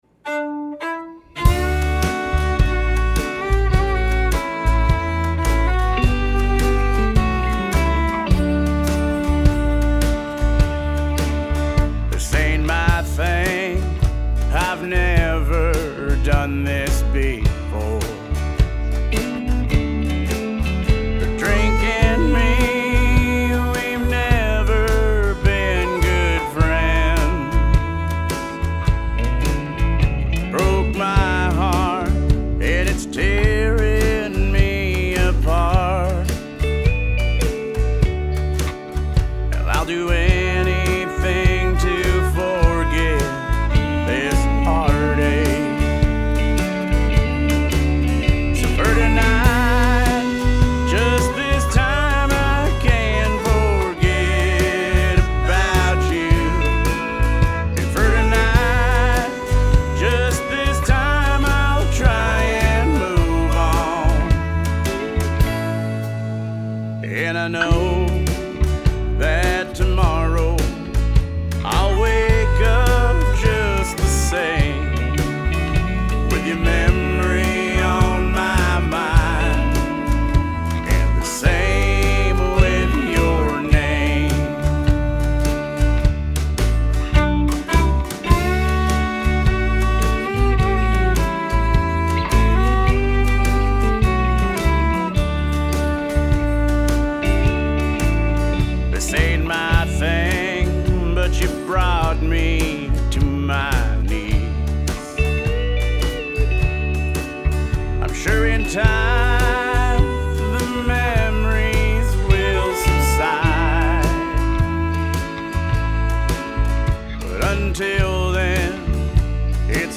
Classic country with a Bakersfield feel